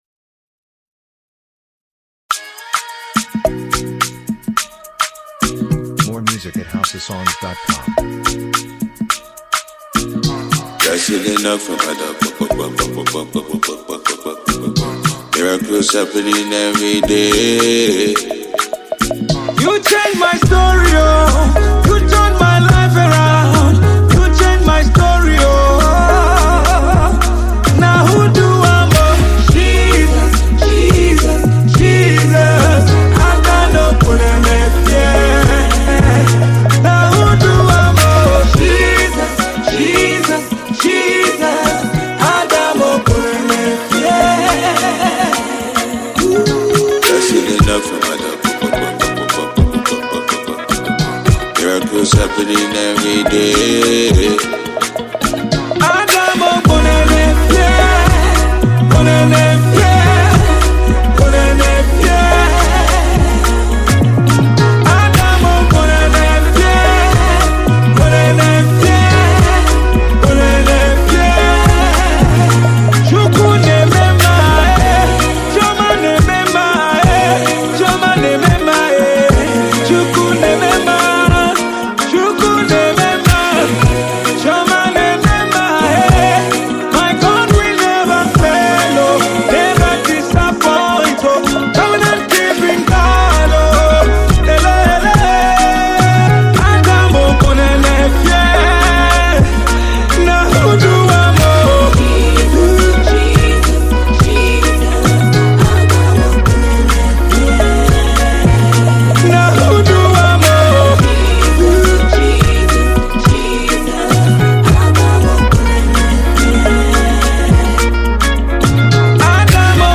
Tiv Song